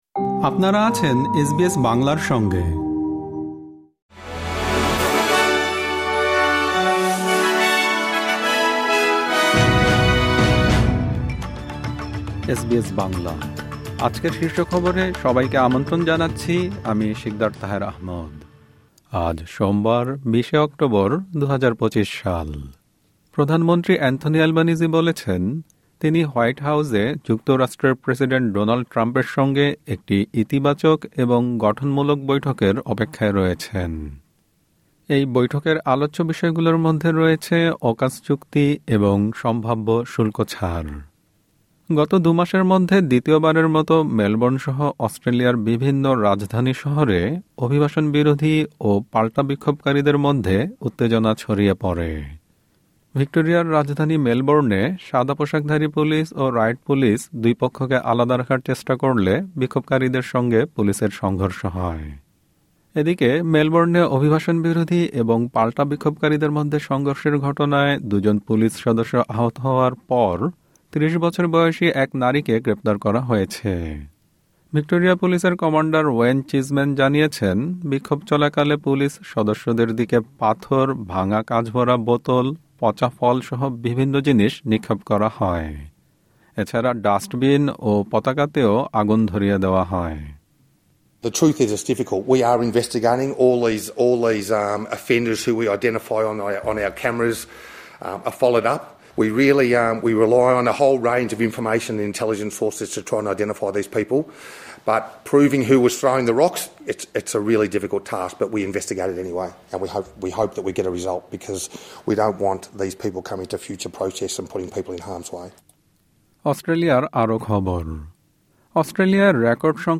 এসবিএস বাংলা শীর্ষ খবর: ২০ অক্টোবর, ২০২৫